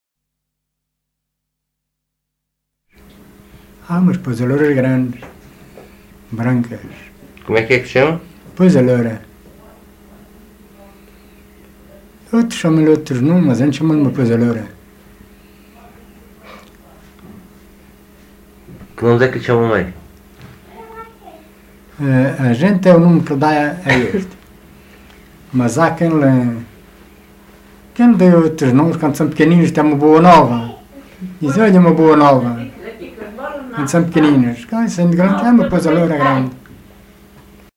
LocalidadeSão Salvador de Aramenha (Marvão, Portalegre)